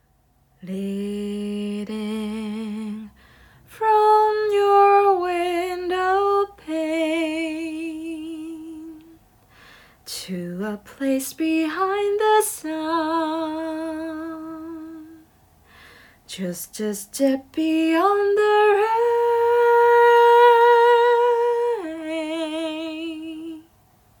ボイスサンプル
歌(英語)